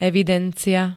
evidencia [-d-] -ie pl. G -ií D -iám L -iách ž.